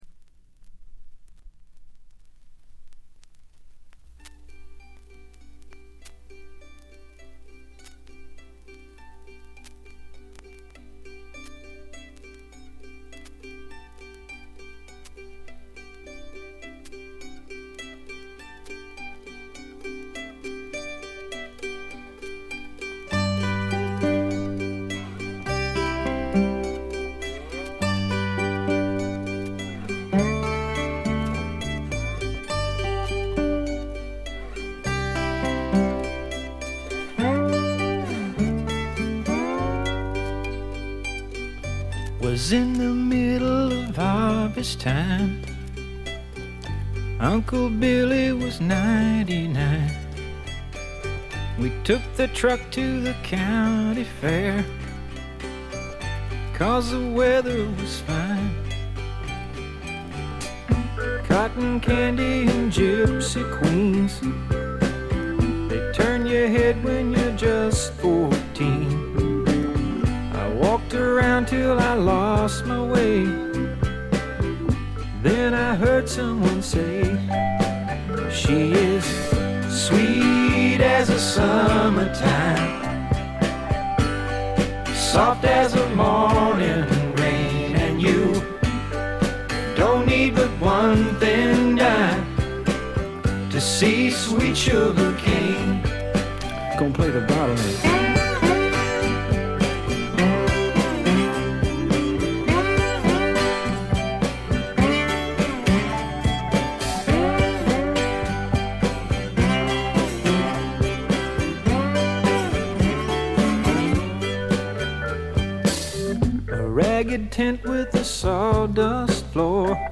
B1冒頭無音部からフェードインするところに「ザッ」という周回ノイズ。
アーシーなシンガー・ソングライターがお好きな方ならば文句なしでしょう。
試聴曲は現品からの取り込み音源です。